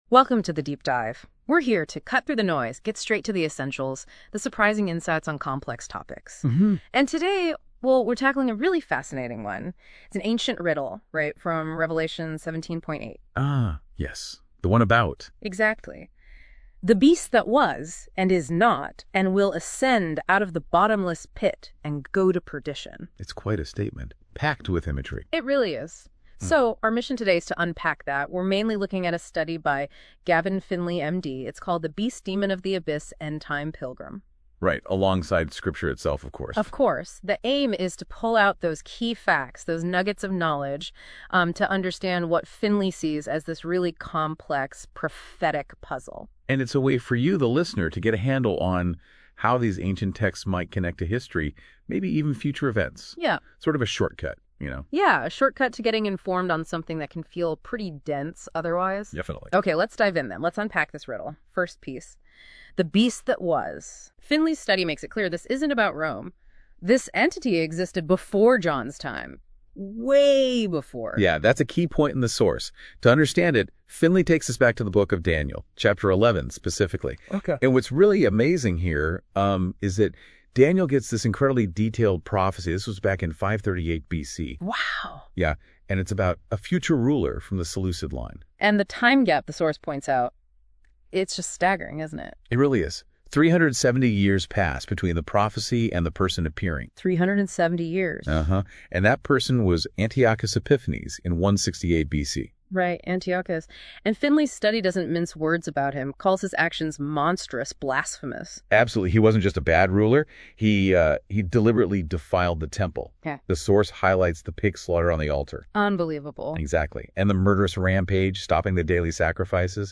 Here is a two-person audio commentaryon this article, from NotebookLM Studio.